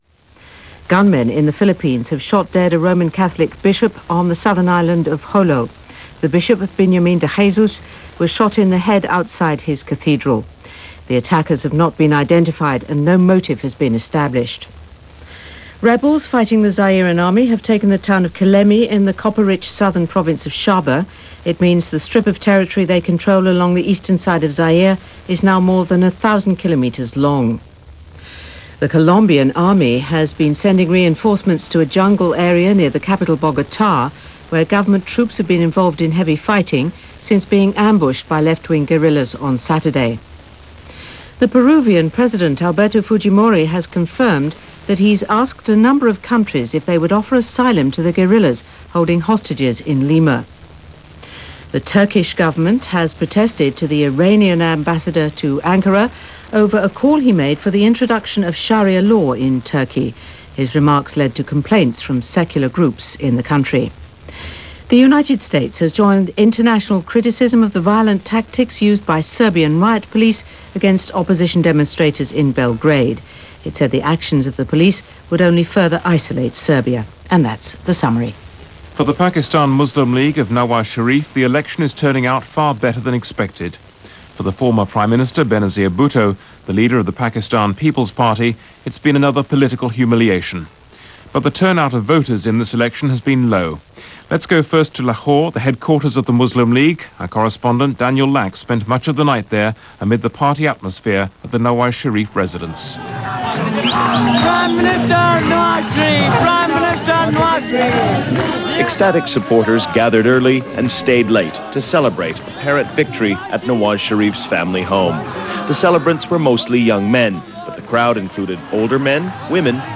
February 4, 1997 – BBC World Service – Newsdesk –
News-BBC-Newsdesk-February-4-1997.mp3